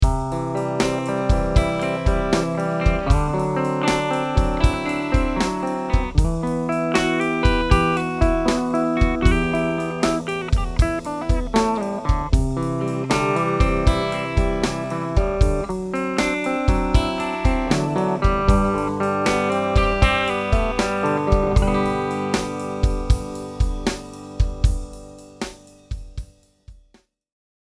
The Burstbucker 3 produces a rich tone that’s full of harmonics and overtones, without being overly bright.
Treble Pickup
Clean
I used a Sennheiser e609 instrument mic, and recorded directly into GarageBand with no volume leveling.
treb_clean.mp3